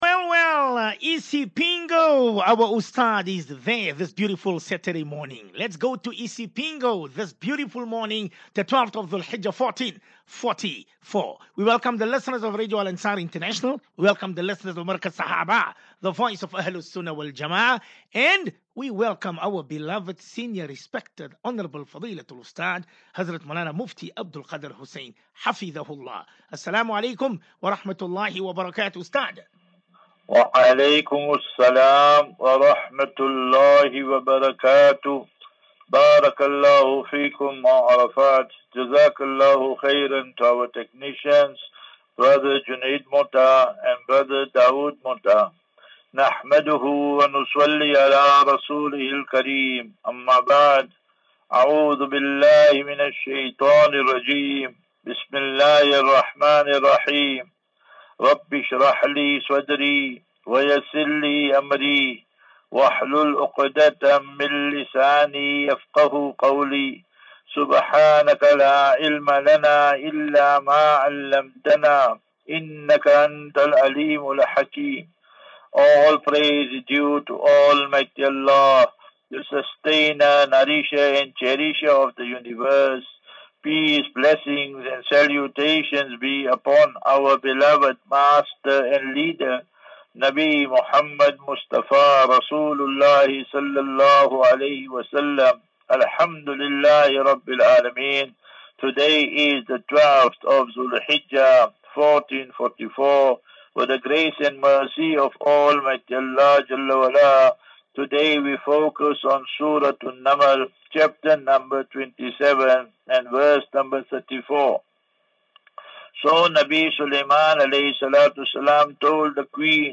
Daily Naseeha.
As Safinatu Ilal Jannah Naseeha and Q and A 1 Jul 01 July 2023.